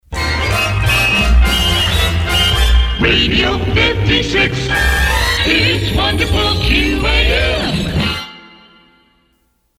These jingles have a "Woody Woodpecker" sounding theme